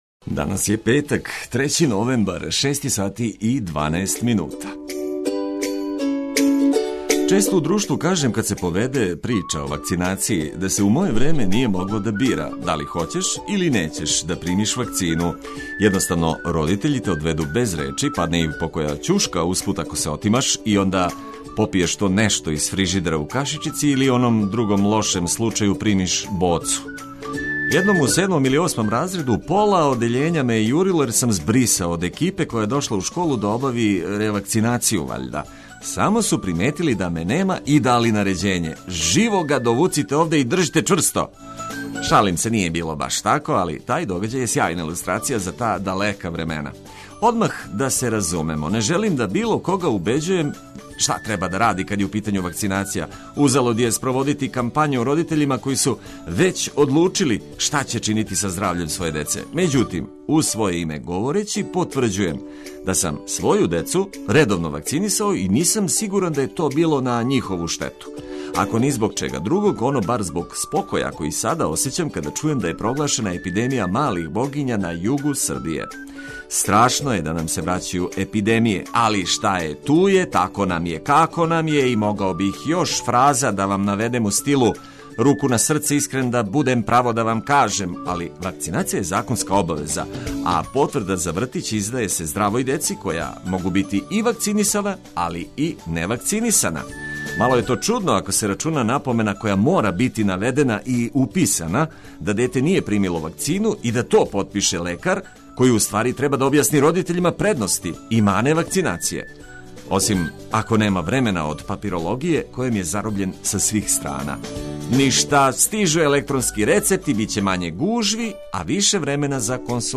Све важне информације на једном месту уз најбољу музику и расположену екипу емисије, одличан су рецепт за лакши корак у нови дан.